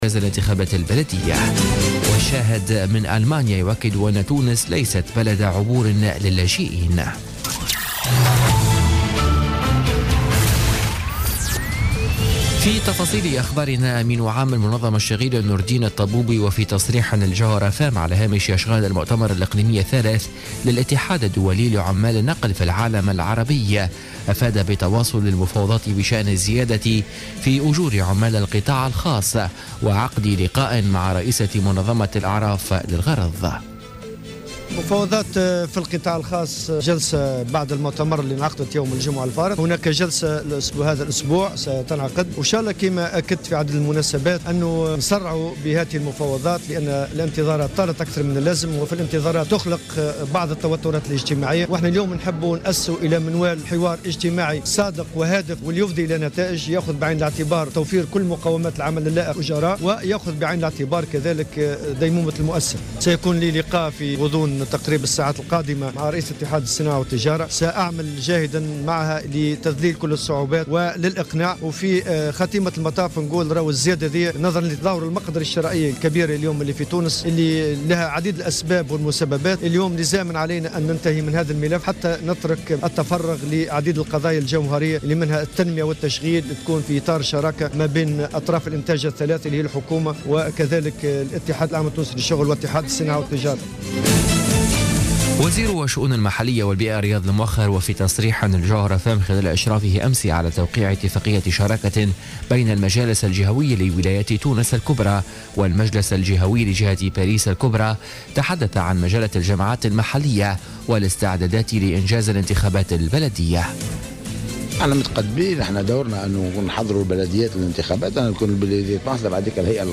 نشرة أخبار منتصف الليل ليوم الاربعاء 15 فيفري 2017